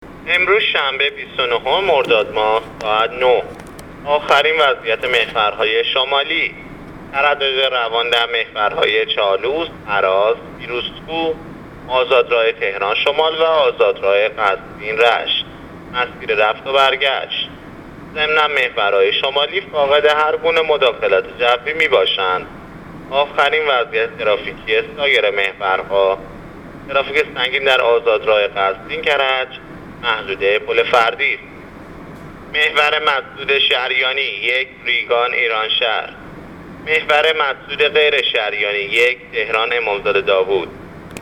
گزارش رادیو اینترنتی از آخرین وضعیت ترافیکی جاده‌ها تا ساعت ۹ بیست و نهم مرداد ماه؛